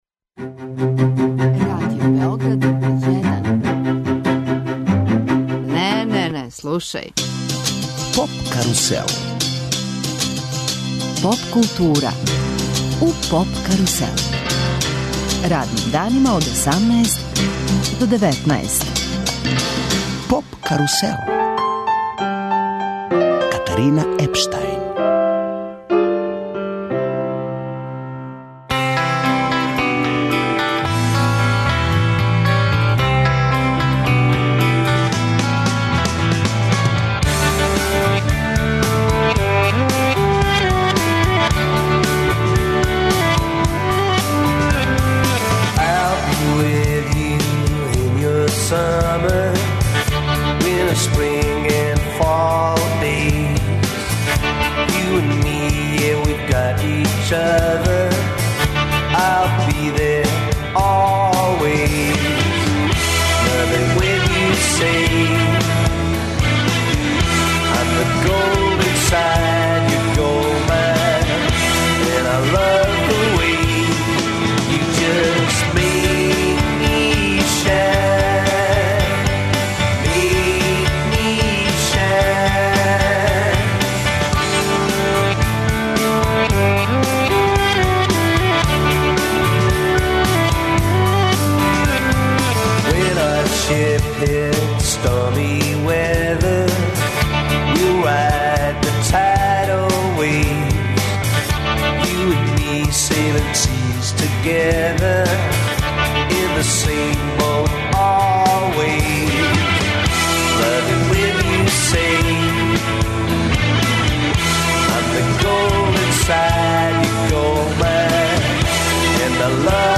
Разговараћемо ексклузивно са члановима овог састава, које смо упознали и слушали ове зиме, током боравка у Лондону.